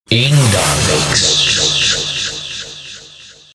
Категория: Скачать Фразы и Произношения